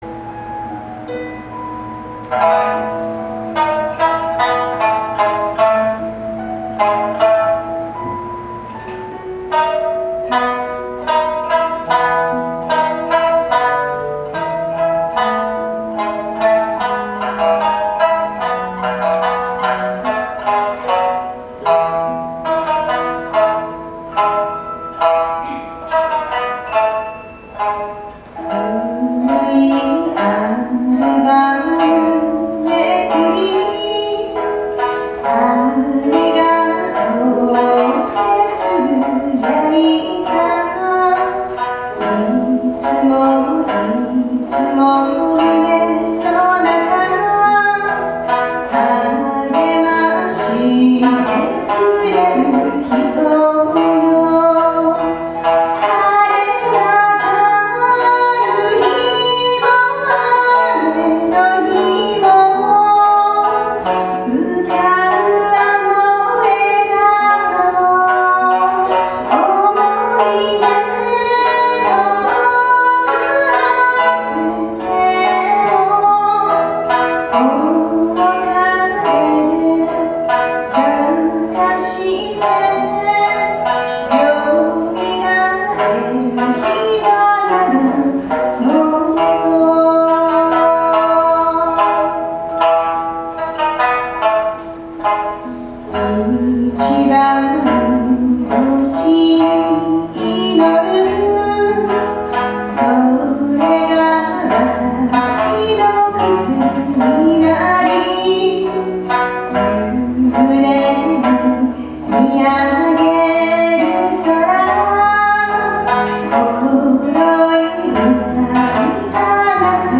お姉さんが唄う